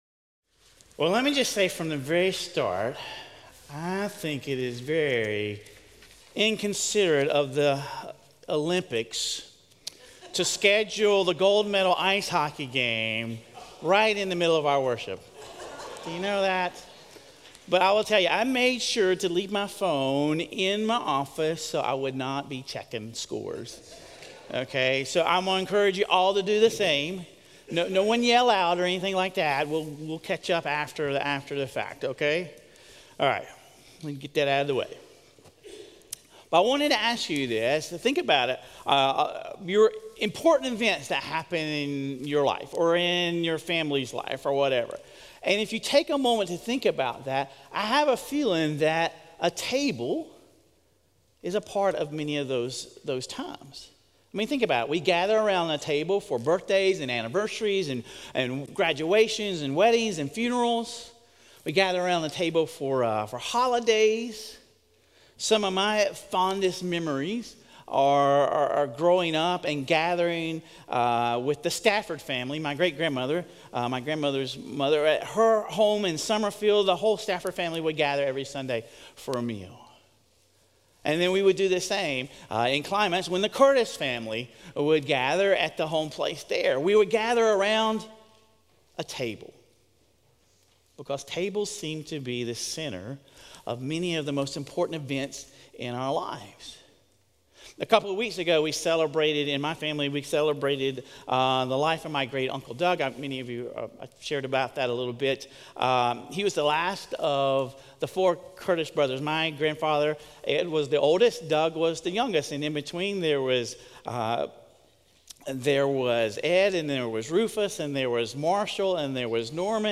Sermon Reflections: